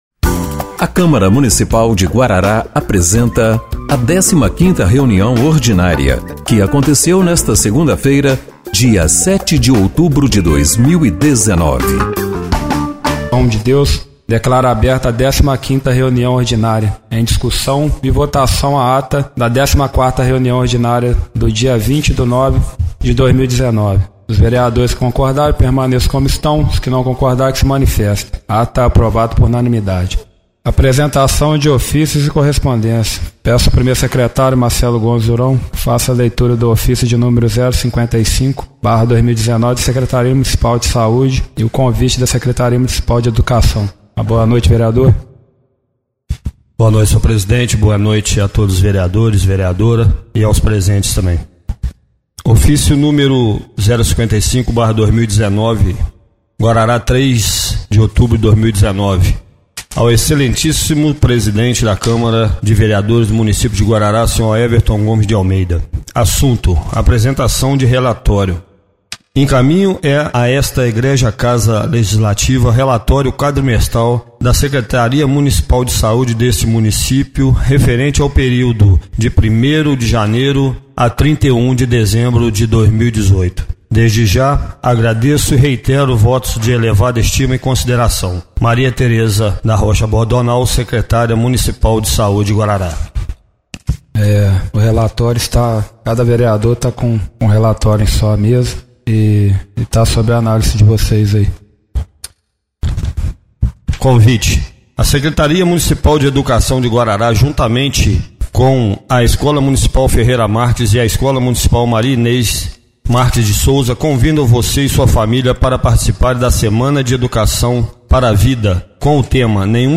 15ª Reunião Ordinária de 07/10/2019